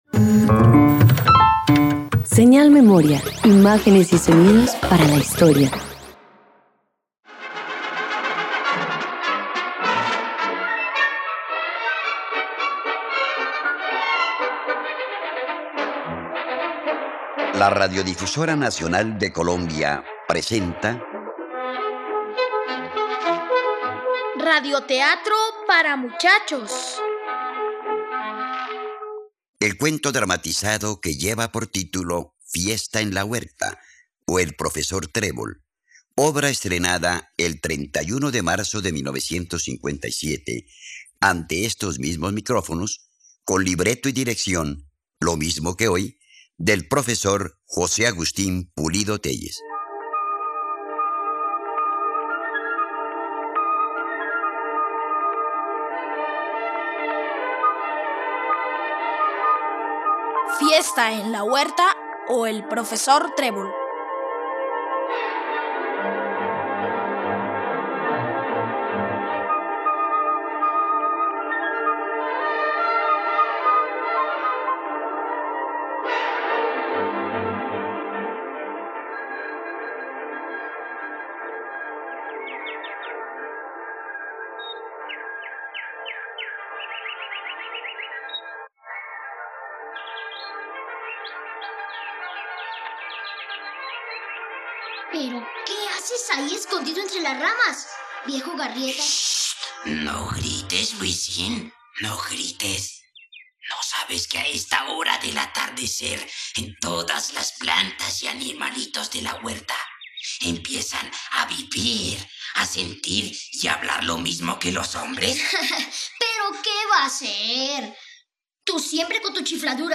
Fiesta en la huerta - Radioteatro dominical | RTVCPlay